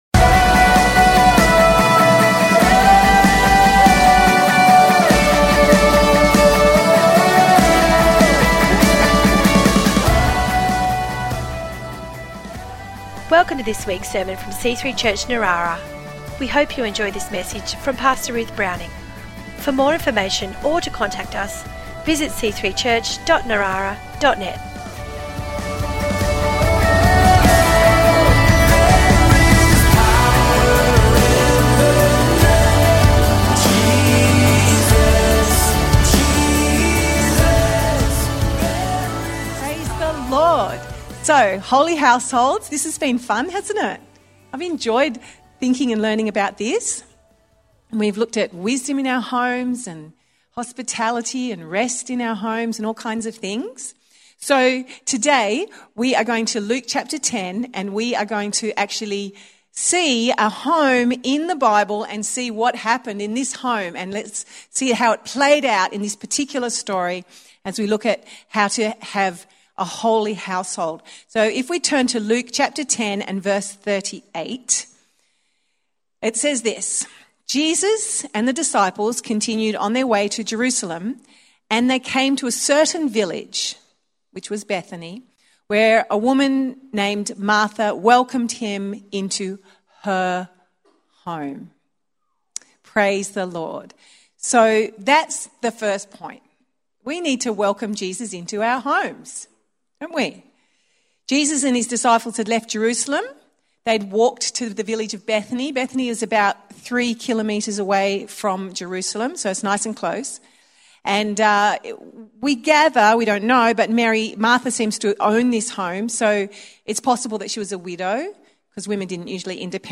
Weekly messages from C3 Church Narara.